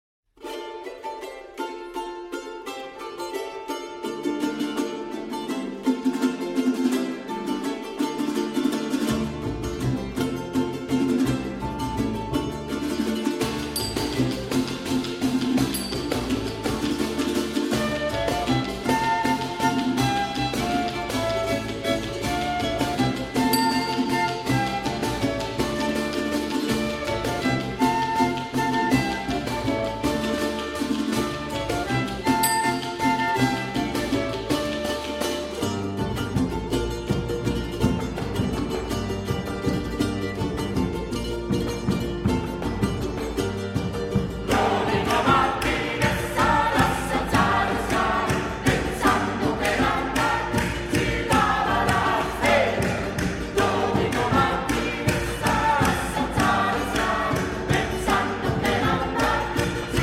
Early music by Sanz
Chamber Ensemble